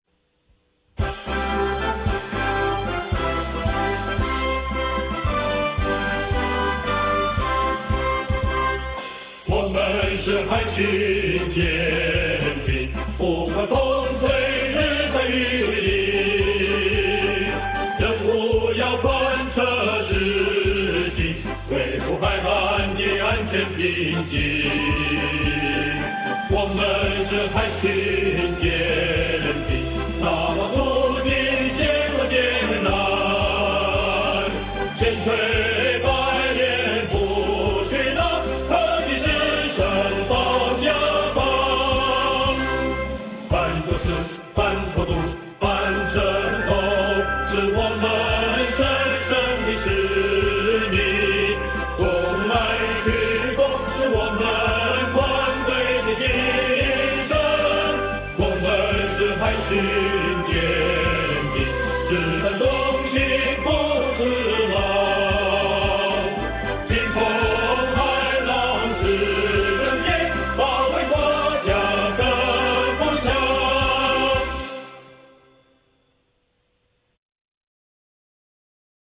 許德萬詞，王昭戎曲
歌唱版RA